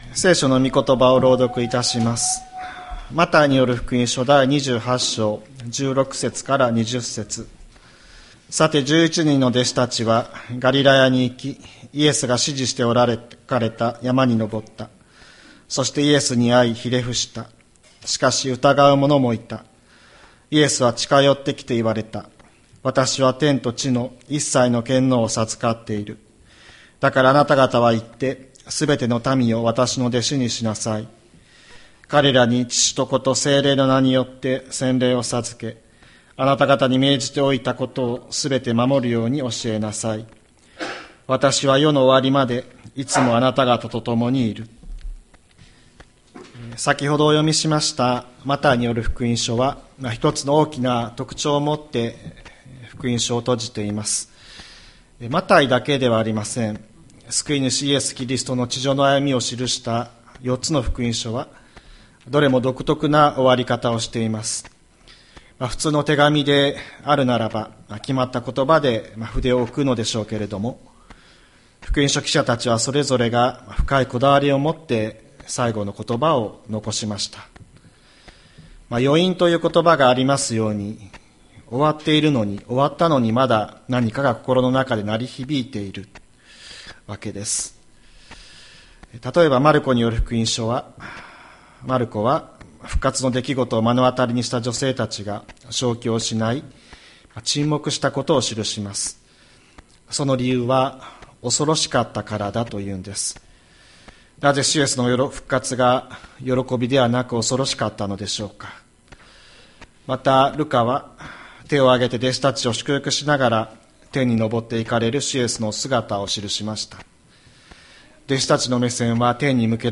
千里山教会 2025年04月27日の礼拝メッセージ。